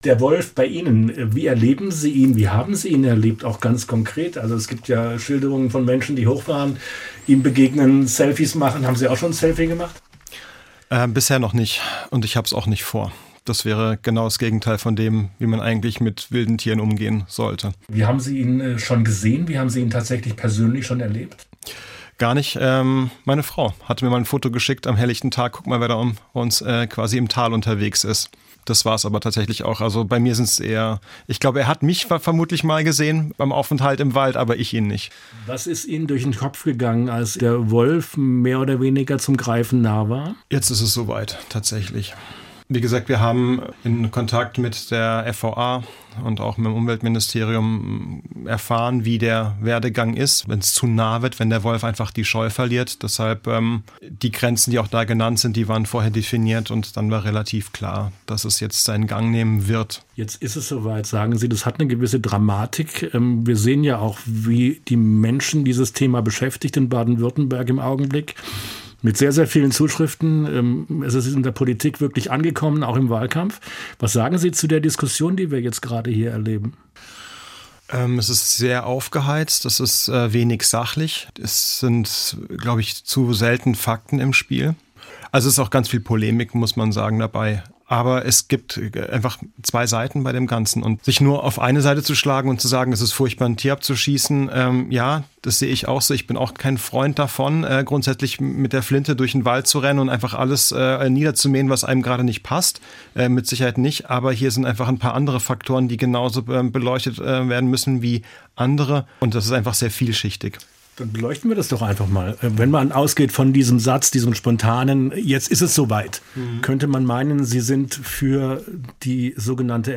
Interview: Wie ein Bürgermeister mit dem Hornisgrinde-Wolf lebt